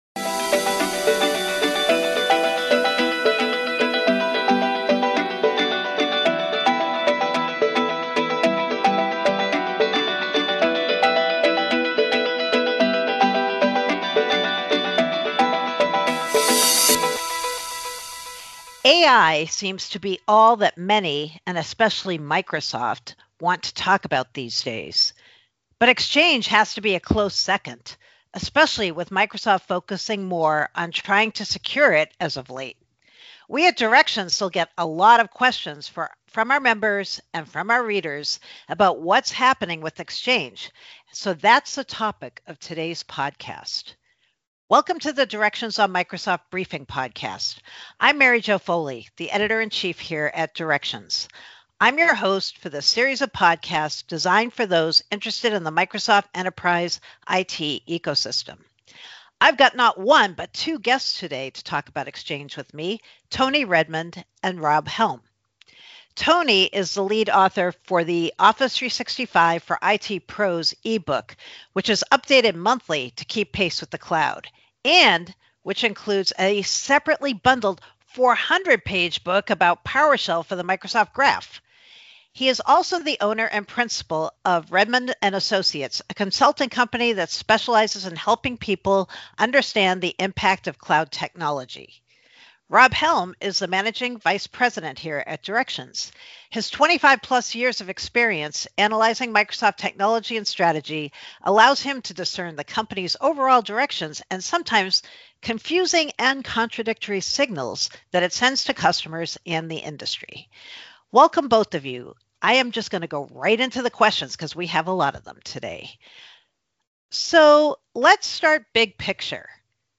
With more than 30 years of experience covering Microsoft, Directions on Microsoft provides unique perspectives about the shifts in Microsoft technologies, roadmaps and licensing policies that matter most to enterprise-size companies. Twice each month industry veteran Mary Jo Foley interviews a different Directions analyst for informed insights and advice to help you derive full value from your Microsoft investments.